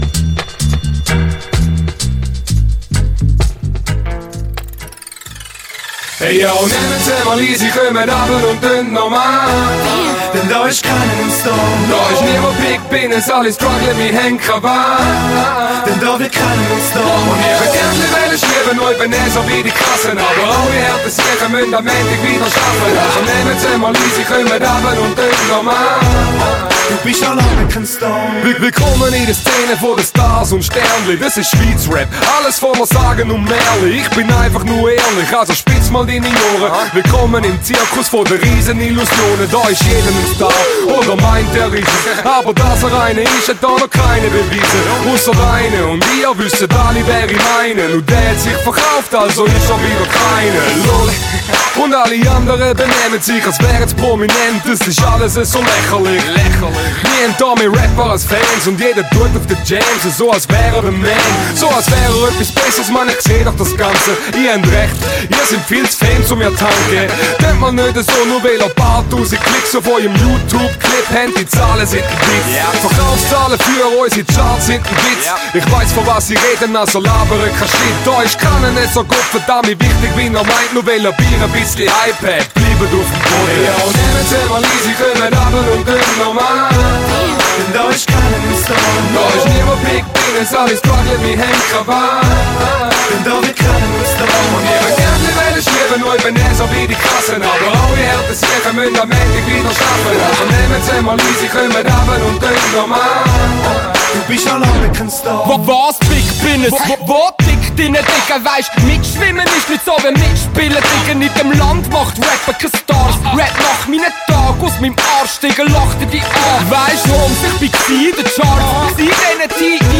Free Underground Web-Radio,
Hip Hop - Schwiitzer RÄP